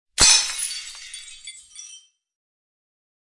Broken Glass Effect.wav